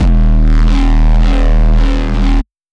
Desecrated bass hit 14.wav